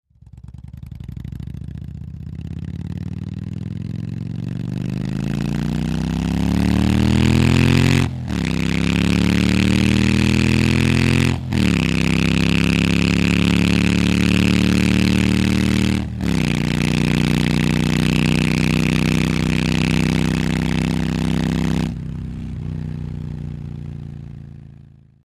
Harley Motorcycle On Board At Various Speeds, With Gear Shifts